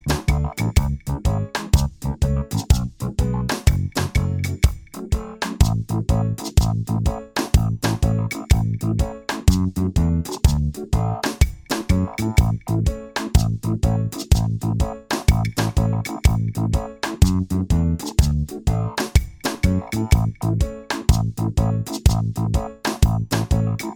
Minus Guitars Reggae 4:26 Buy £1.50